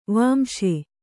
♪ vāmśe